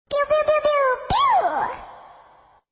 可爱的 biubiubiubiu ~2 - 素材仓库